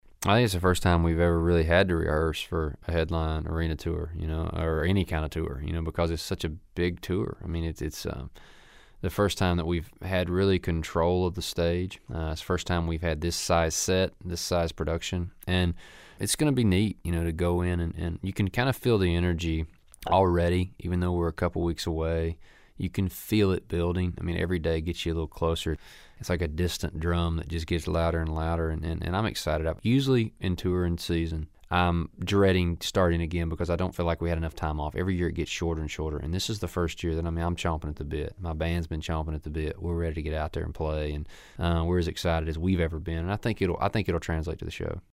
AUDIO: Eric Church talks about rehearsing for his upcoming Blood, Sweat & Beers Tour.